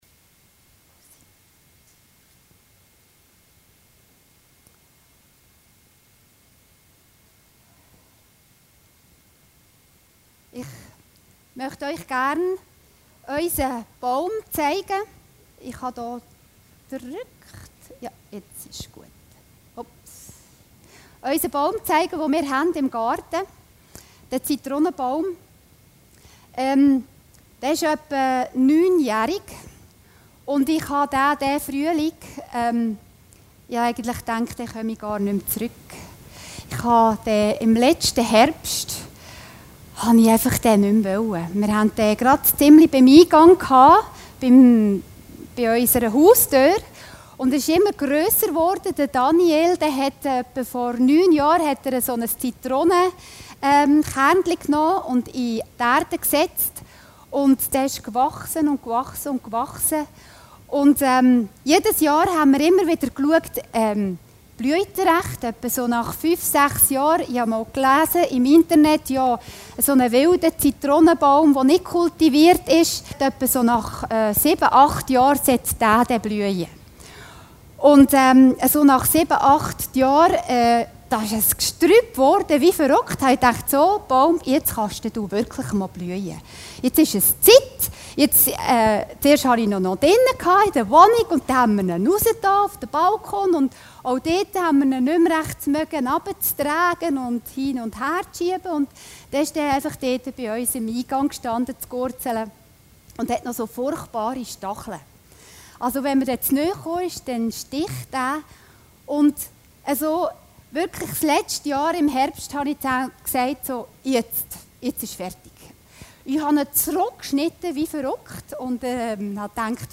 Predigten Heilsarmee Aargau Süd – Unter welchem Baum stehe ich?